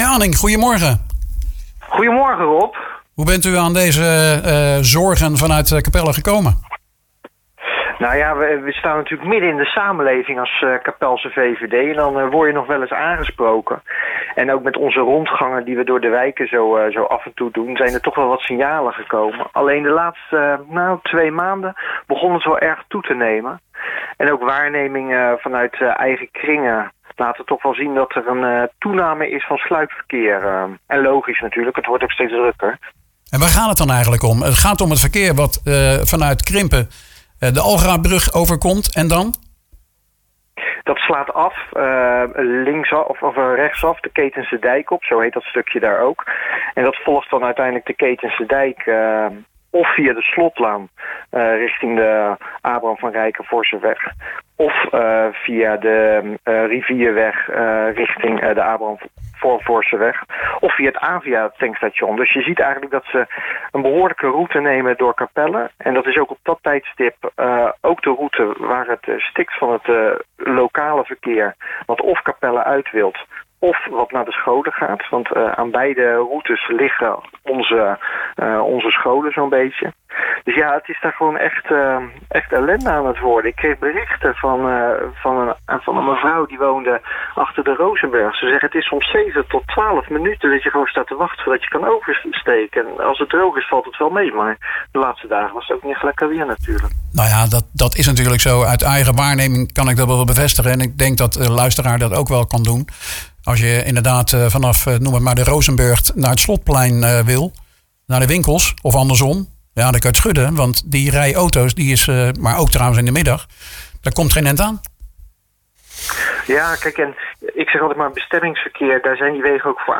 praat met VVD raadslid Leon Anink.